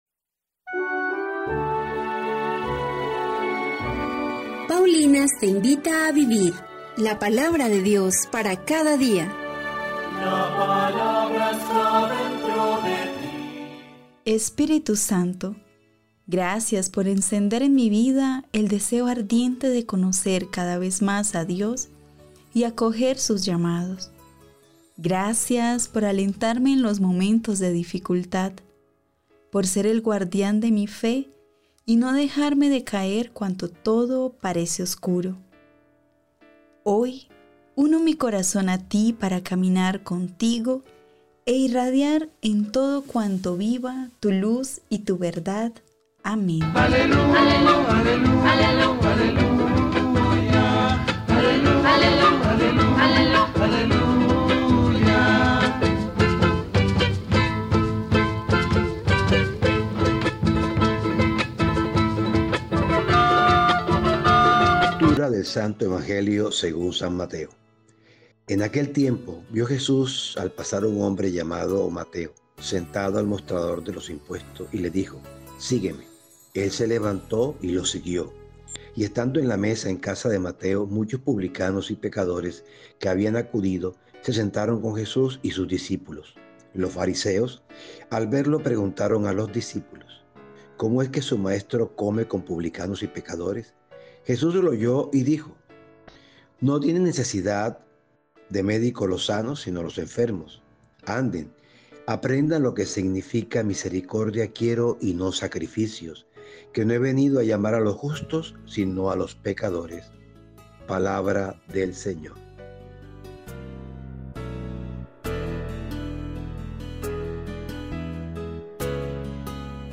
Liturgia-21-de-Septiembre.mp3